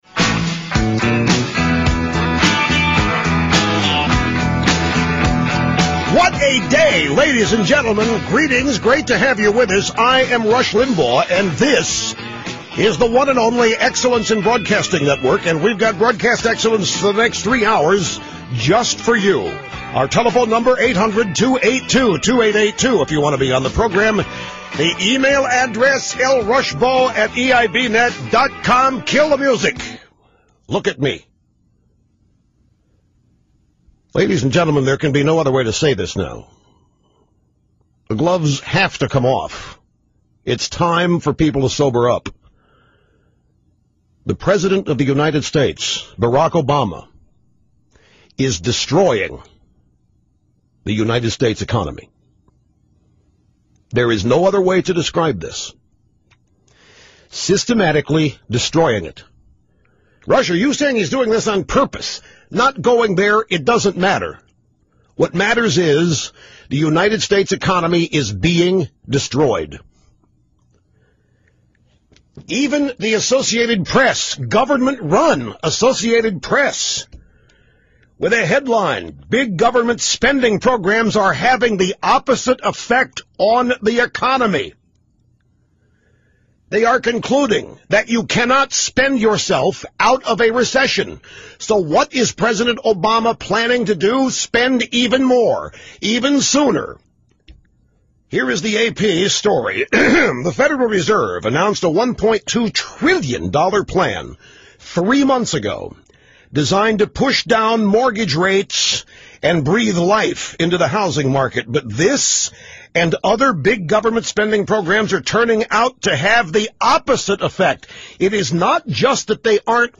The absence of any self-doubt. The pop radio pace at the outset.
The loss of the backing music. The gathering of momentum in his invective.